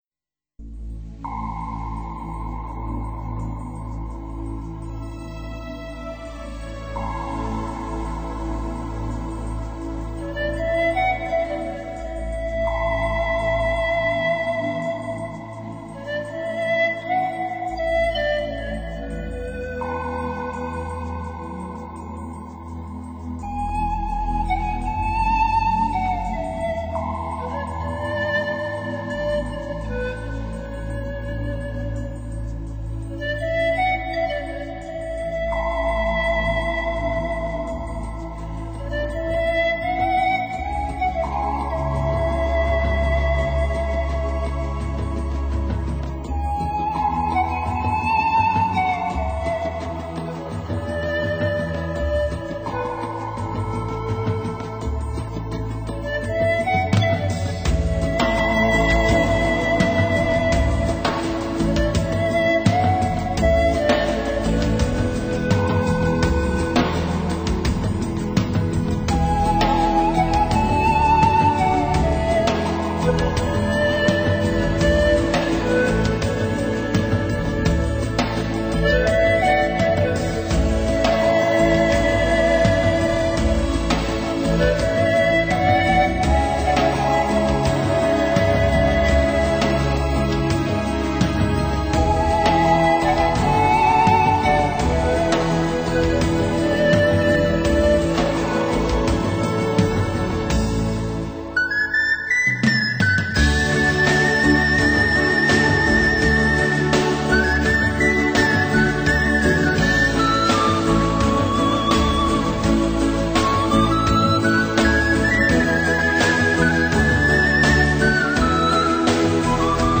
收录失传300年的瑞典锯琴上有长笛竖笛排笛吉他等演奏